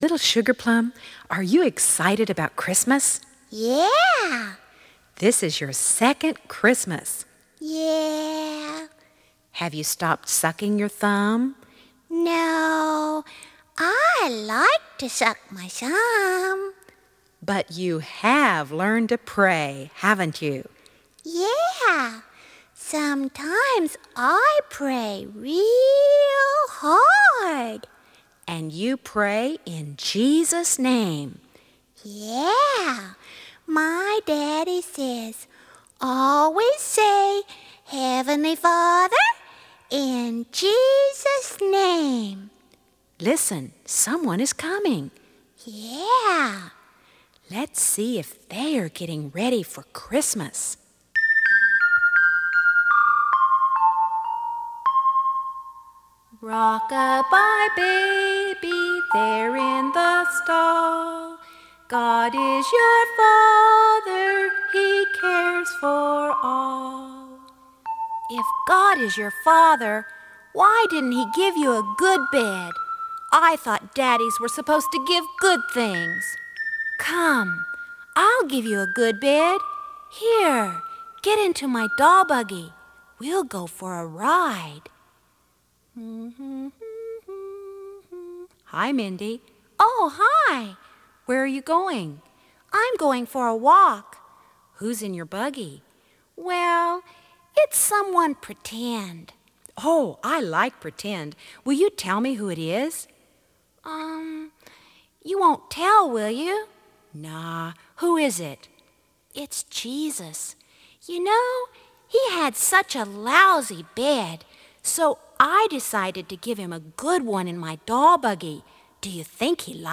original and traditional Bible songs